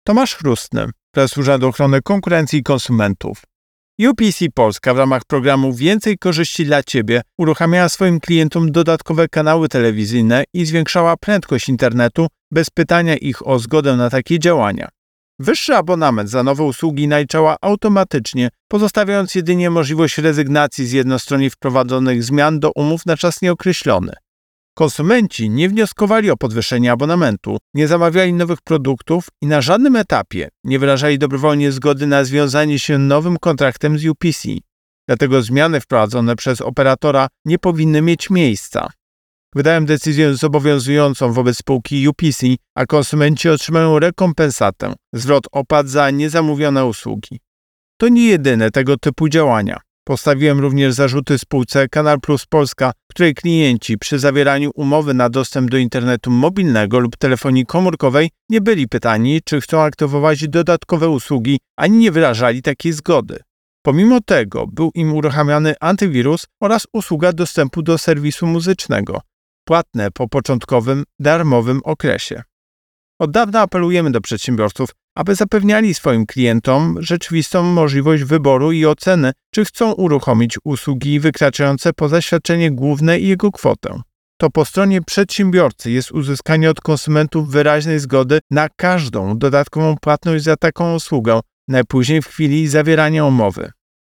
Wypowiedź Prezesa UOKiK Tomasza Chróstnego Co istotne, brak sprzeciwu po stronie konsumentów nie może być odbierany jako milcząca zgoda na działania przedsiębiorcy.